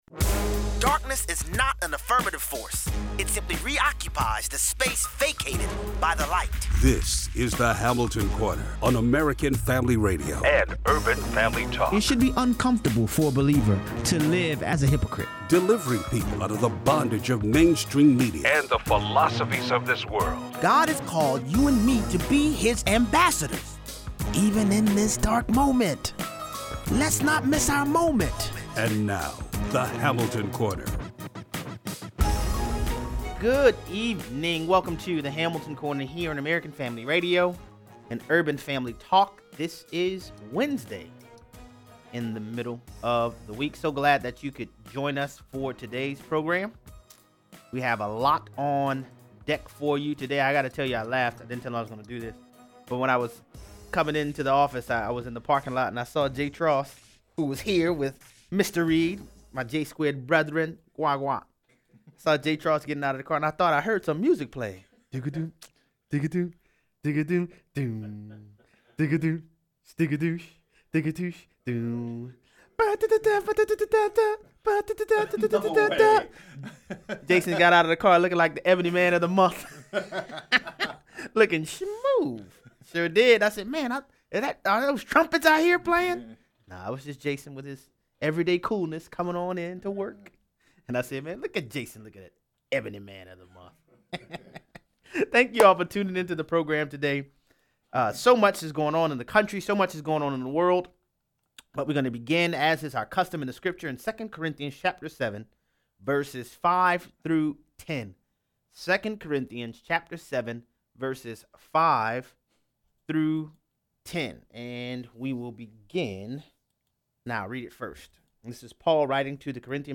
Obama judge blocks Mississippi’s 15 week ban on abortion...but not so fast! 0:43 - 0:60: Good guy with a gun stops violent would be armed robber in Aurora, CO. Callers weigh in.